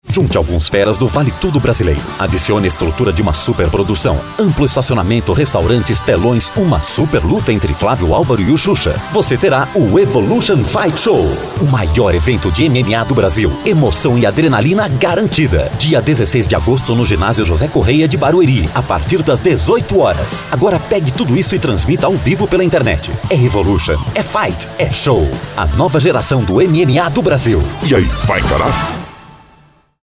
Masculino
Chamada de rádio para evento de tecnologia voltada para templos e igrejas: CHURCH TECH EXPO
Com o estúdio montado em casa, acústica bem cuidada e equipamentos de primeira linha, posso garantir o ótimo nível de qualidade técnica do trabalho.